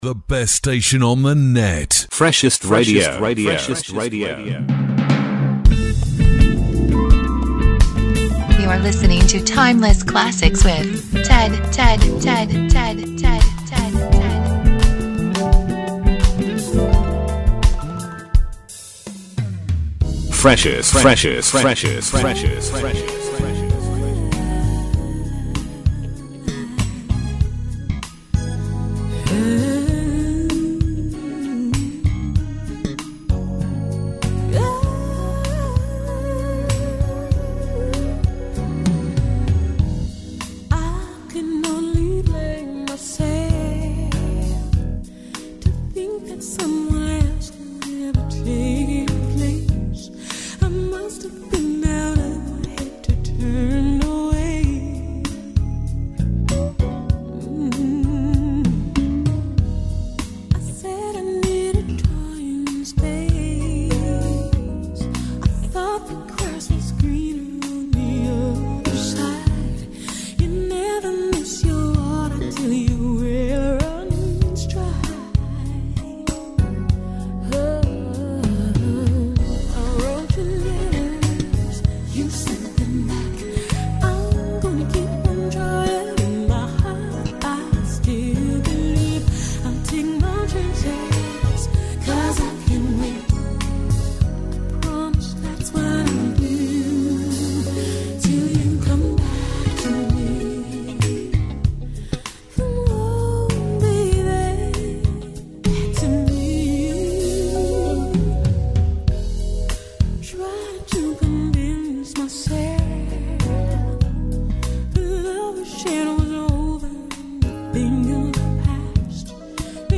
A show for classic R&B music lovers.